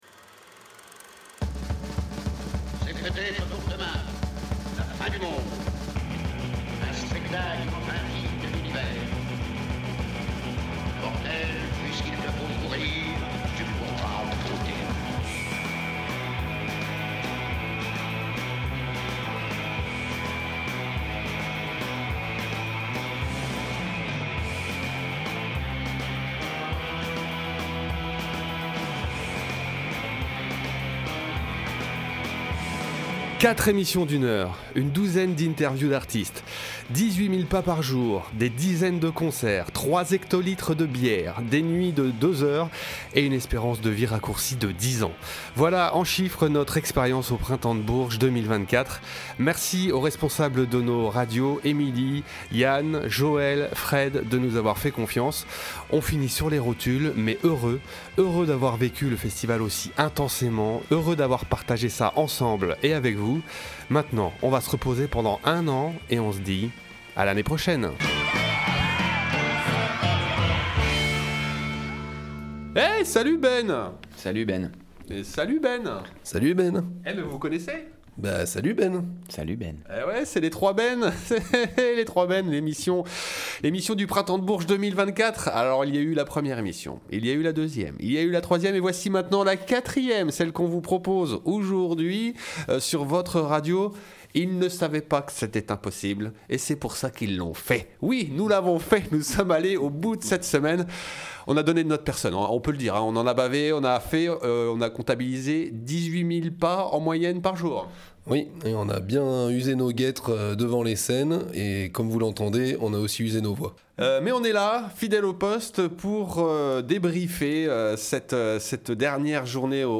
Report au Printemps de Bourges (57:00)